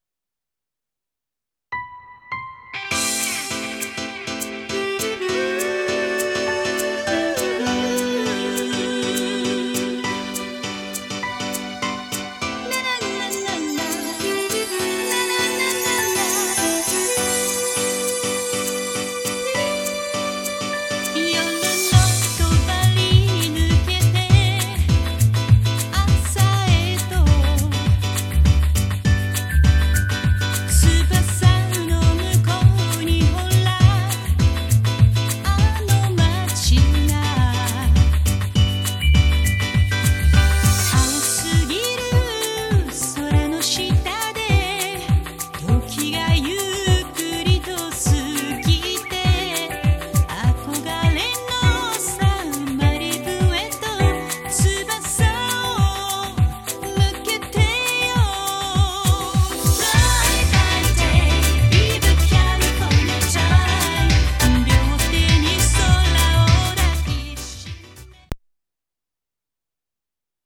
・オリンパス ステレオICレコーダー LS-20M：リニアPCM 44.1kHz/16bit 無圧縮.WAV
オーディオケーブル直結
iPod touchのイヤフォン端子から直接LS-20MのMIC端子に接続しています。